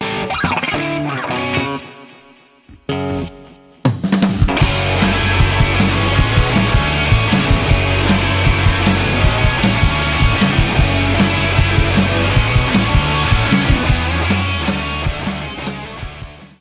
like this is a little thing with essentially a jcm800 and soldano 100 and some reverb etc. i think the compression of clipping it fucked up the drums but you get the idea hopefully!